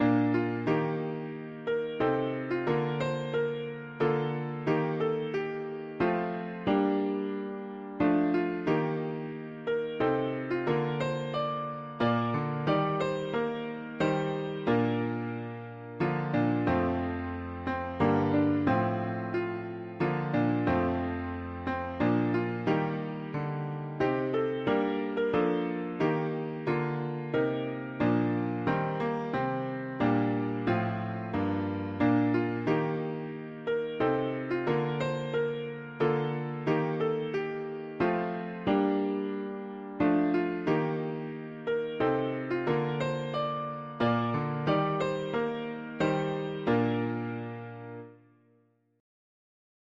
Key: B-flat major Meter: 13.13.13 D